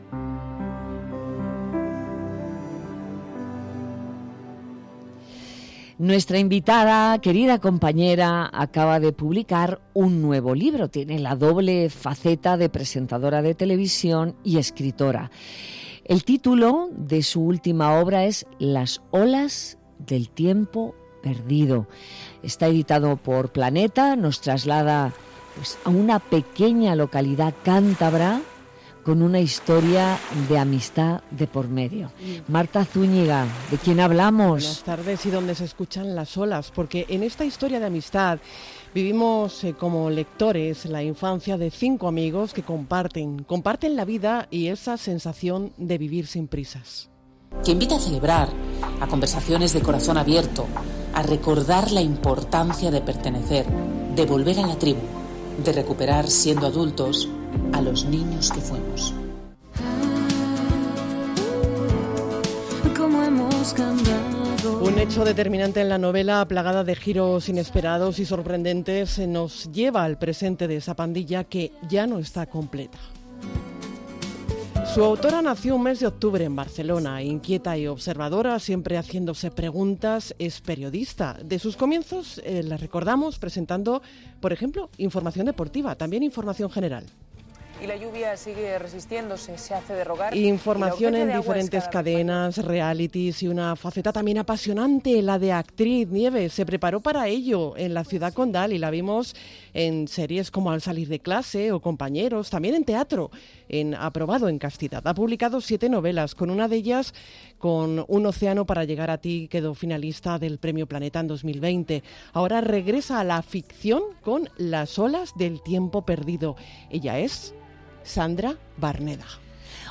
Barneda ha pasado por Madrid Directo de Onda Madrid con Nieves Herrero, donde ha detallado el argumento de este último libro, que es un homenaje a la amistad y a la importancia de recuperar, siendo adultos, a los niños que fuimos.